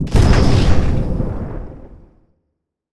sci-fi_explosion_03.wav